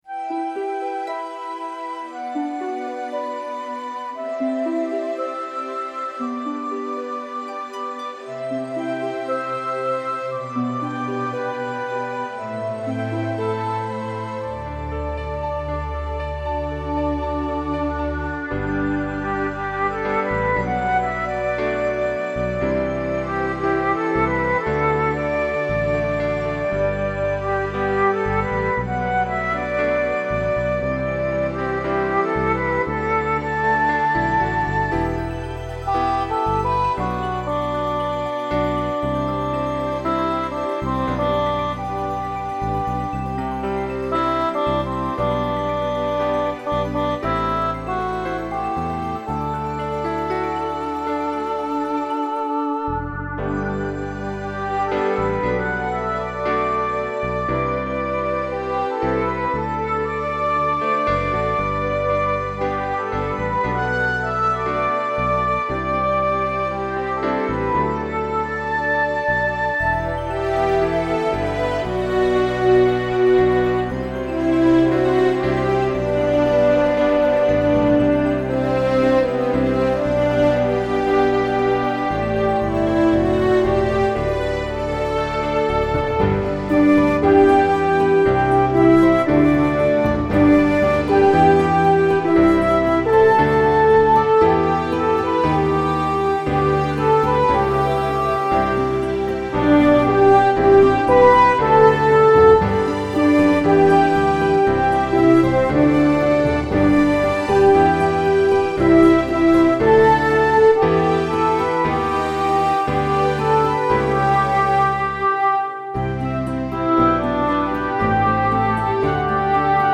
Below I share an instrumental version of my song.
the-unknown-2-instrumental.mp3